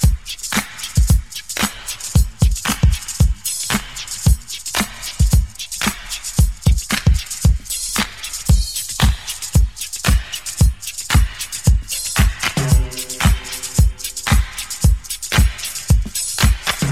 113 Bpm Drum Groove C Key.wav
Free drum beat - kick tuned to the C note. Loudest frequency: 2804Hz
113-bpm-drum-groove-c-key-LME.ogg